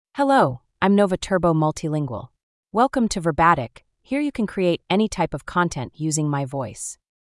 Nova Turbo MultilingualFemale English AI voice
Nova Turbo Multilingual is a female AI voice for English (United States).
Voice sample
Listen to Nova Turbo Multilingual's female English voice.
Nova Turbo Multilingual delivers clear pronunciation with authentic United States English intonation, making your content sound professionally produced.